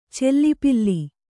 ♪ cellipilli